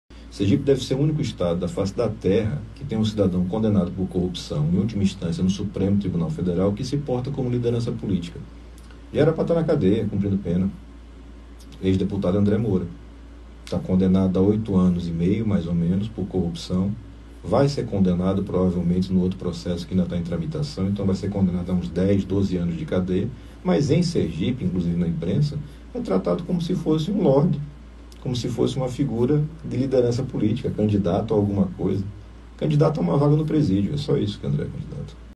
Em entrevista a uma emissora de rádio da capital, o senador Alessandro Vieira (Cidadania), pré-candidato a Presidência da República afirmou que “Sergipe deve ser o único estado da face da Terra que tem um cidadão condenado por corrupção em última instância no STF que se porta como liderança política”, disse.